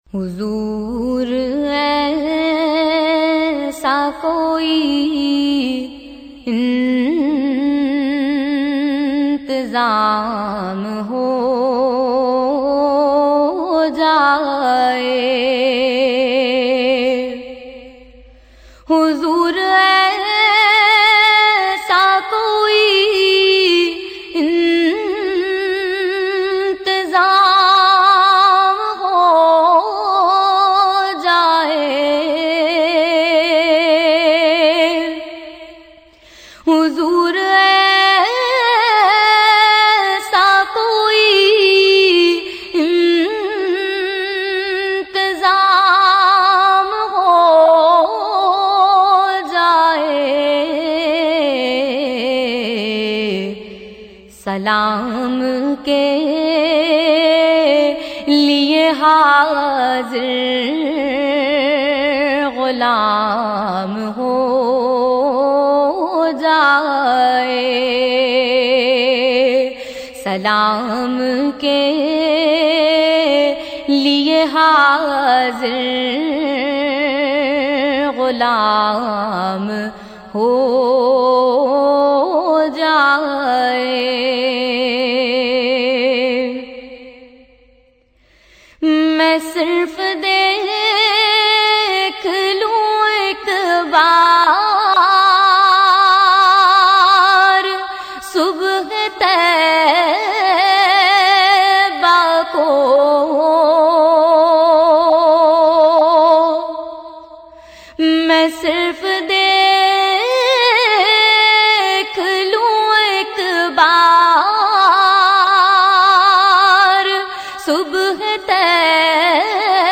Naat Sharif
in a melodious and soothing voice